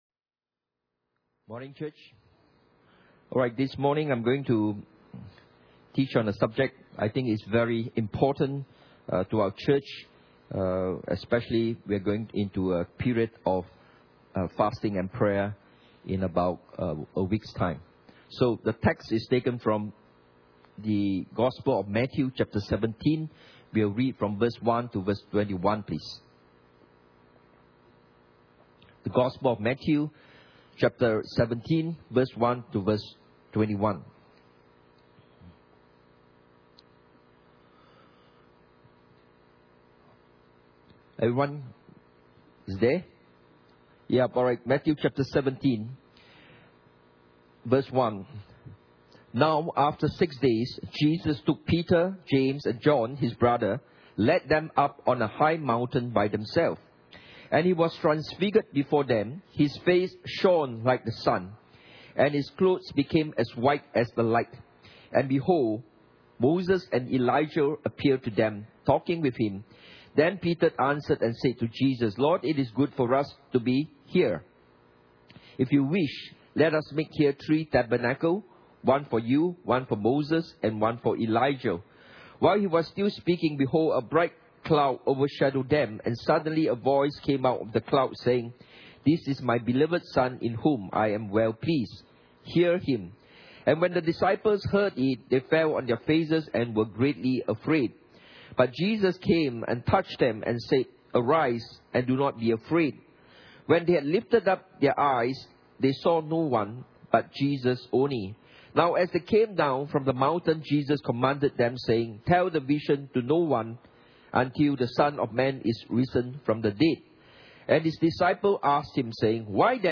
The Spiritual Nuclear Power of Prayer and Fasting Service Type: Sunday Morning « What are your Dreams?